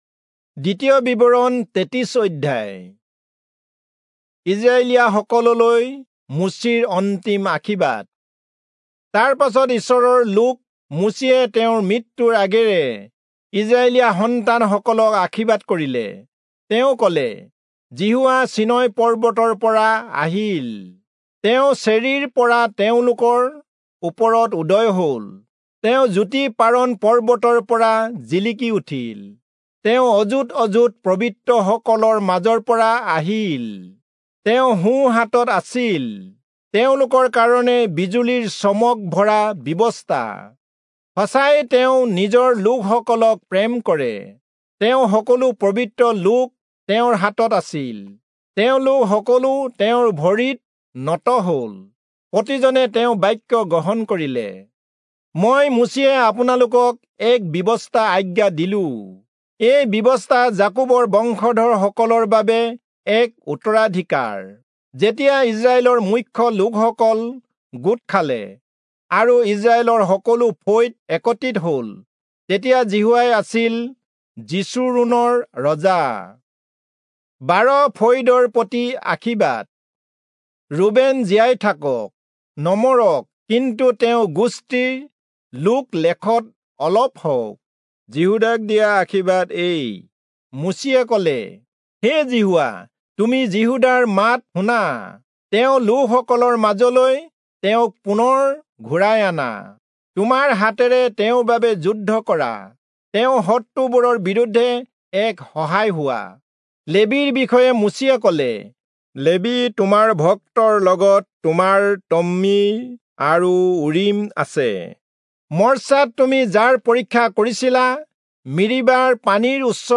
Assamese Audio Bible - Deuteronomy 23 in Tov bible version